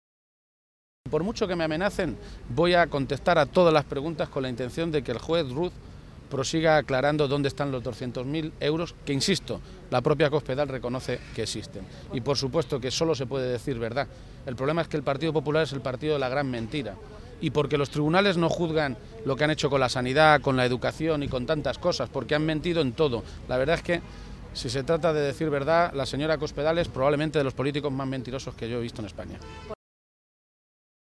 García-Page se pronunciaba de esta maneta esta mañana, en Toledo, a peguntas de los medios de comunicación, horas antes de que declare como testigo en la Audiencia Nacional en el marco de las investigaciones de los llamados “papeles de Bárcenas”.
Cortes de audio de la rueda de prensa